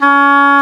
WND D OBOE01.wav